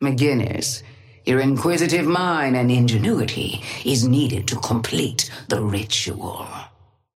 Sapphire Flame voice line - McGinnis, your inquisitive mind and ingenuity is needed to complete the ritual.
Patron_female_ally_forge_start_05.mp3